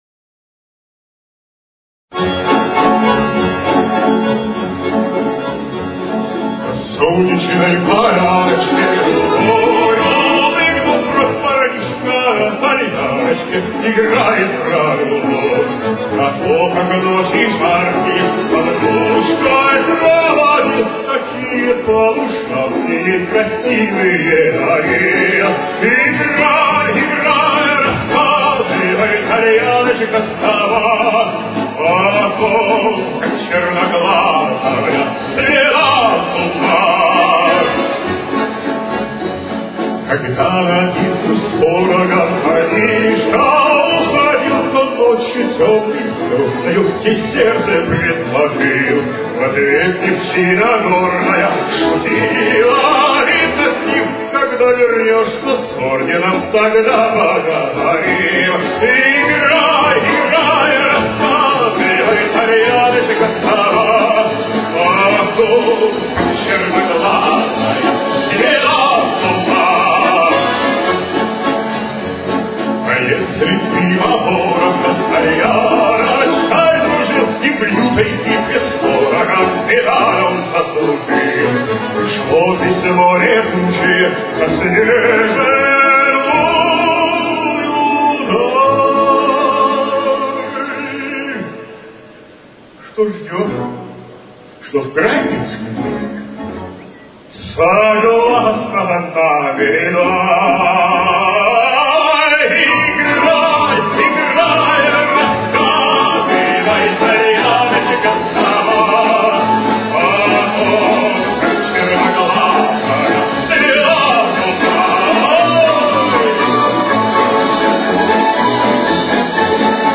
Темп: 107.